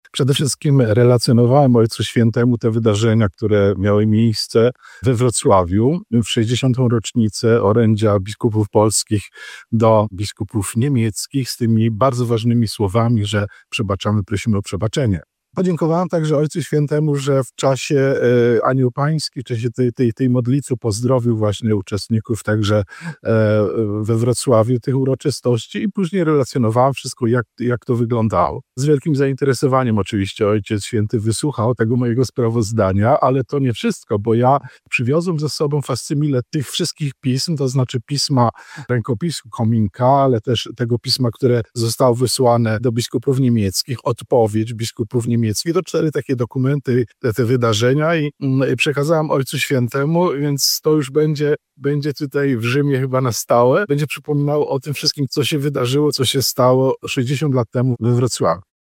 02_Abp-Kupny-po-audiencji.mp3